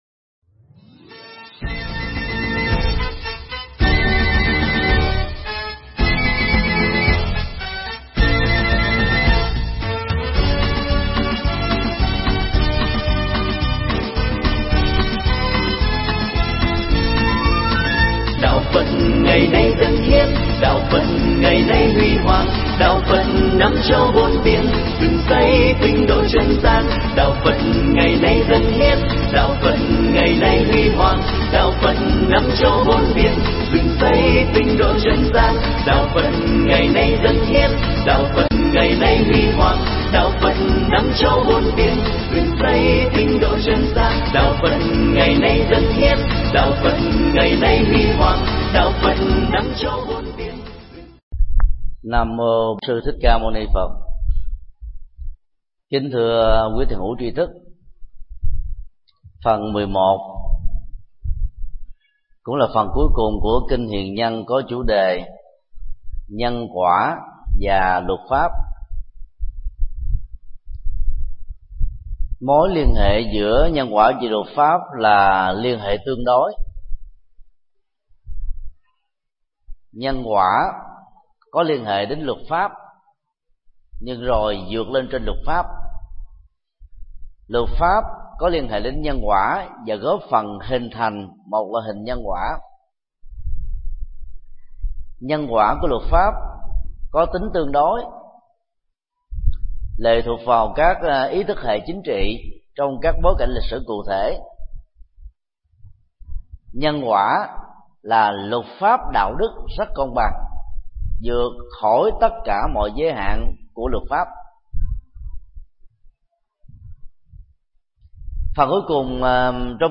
Mp3 Thuyết Pháp Kinh Hiền Nhân 11
giảng tại chùa Xá Lợi ngày 19 tháng 8 năm 2012